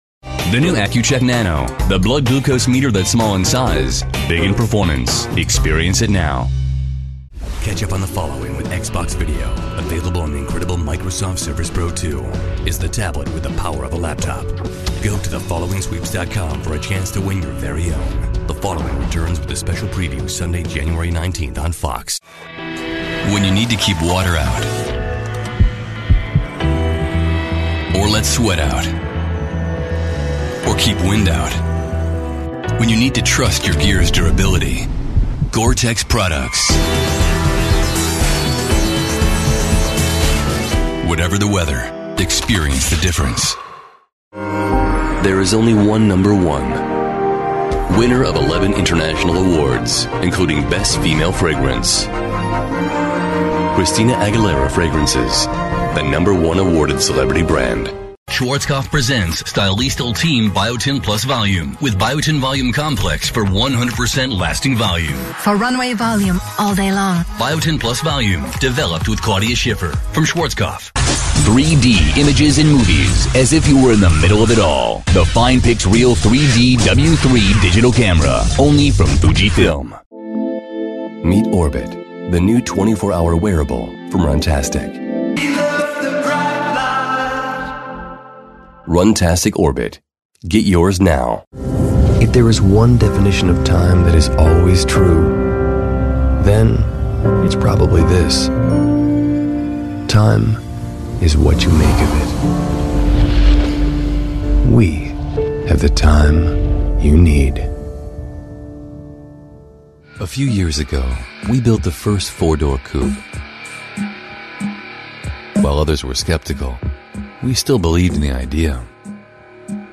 Sprecher amerikanisch englisch. Werbesprecher. Stimmfarbe: Tief, sexy, erfahren.
mid-atlantic
Sprechprobe: Werbung (Muttersprache):